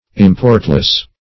Importless \Im*port"less\, a.